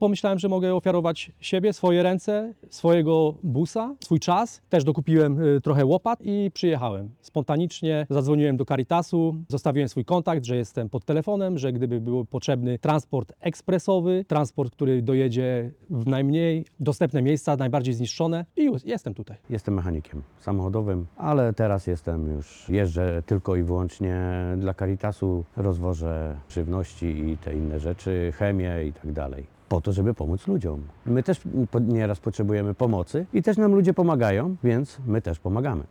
Na miejscu już od pierwszych dni działają wolontariusze: